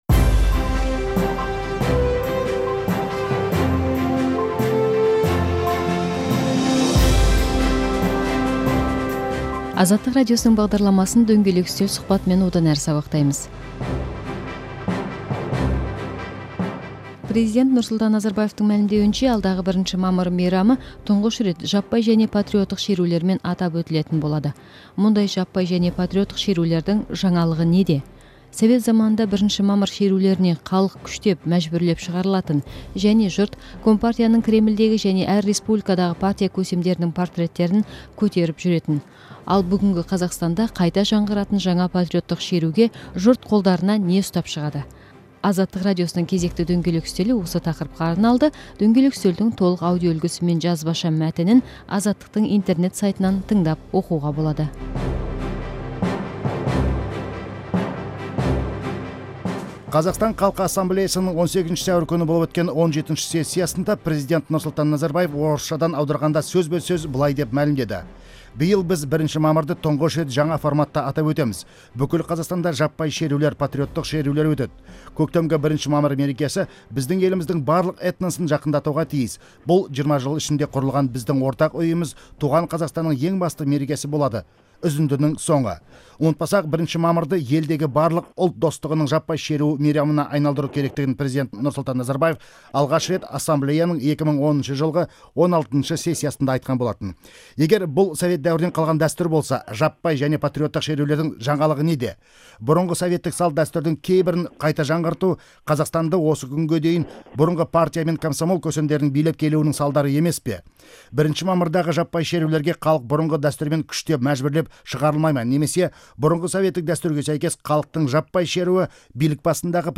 1 мамыр мерекесіне қатысты пікірталасты тыңдаңыз